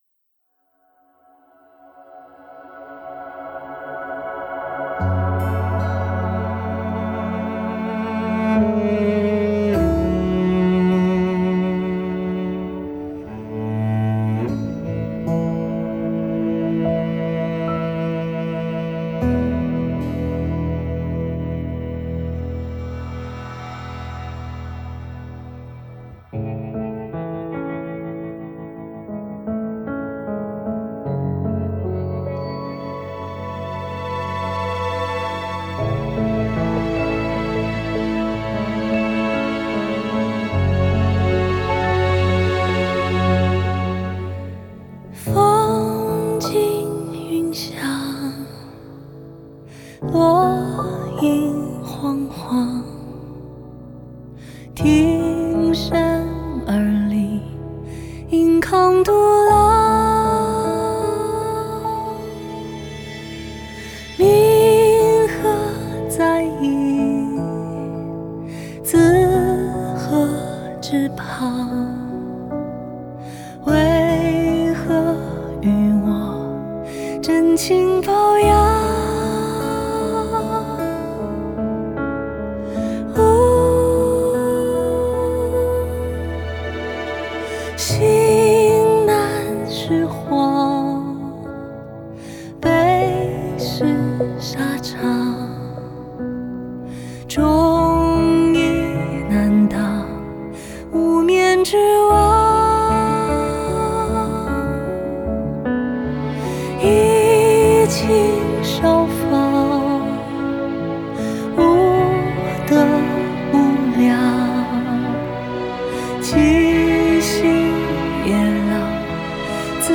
弦乐Strings
和声Background Vocalist